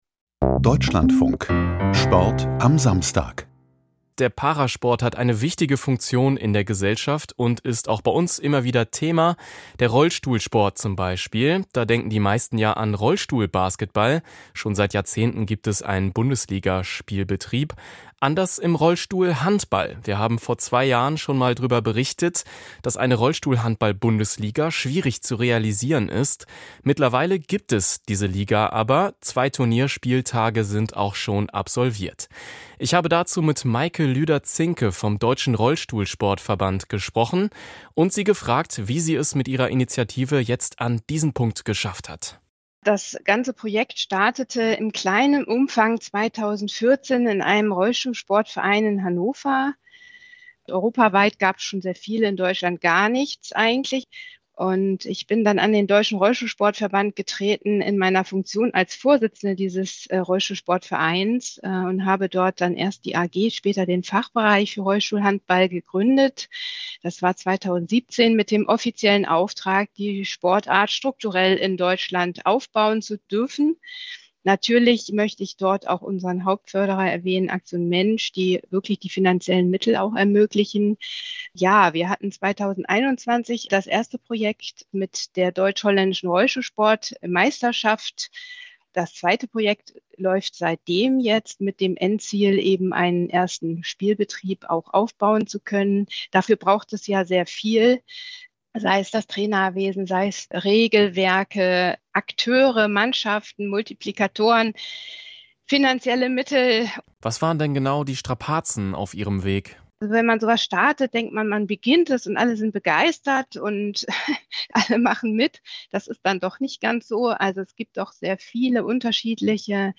Rollstuhlhandball im Interview bei Deutschlandfunk